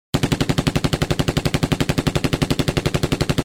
机枪开枪声.mp3